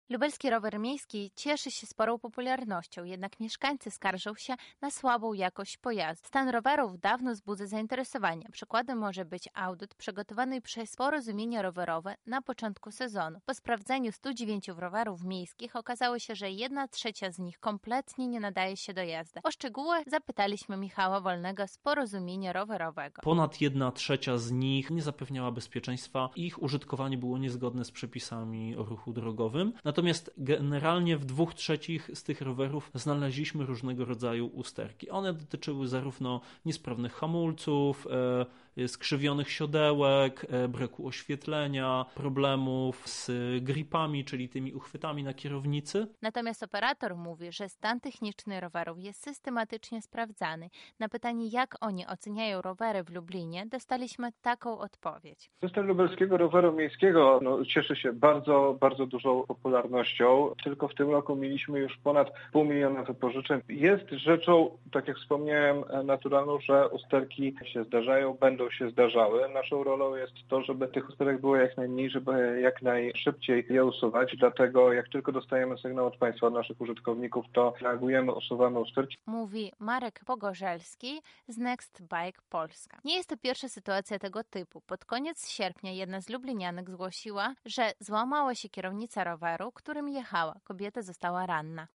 Szczegóły zna nasza reporterka